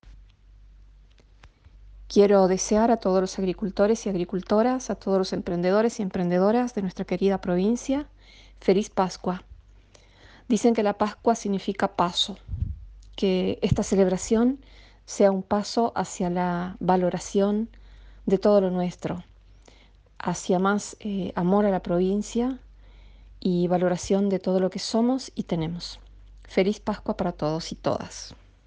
Exclusiva: Por medio de la Agencia de Noticias Guacurarí, la Ministra de Agricultura Familiar de la provincia, Marta Ferreira, deseó unas Felices Pascuas a todos los agricultores/as y emprendedores/as de la provincia.